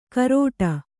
♪ karōṭa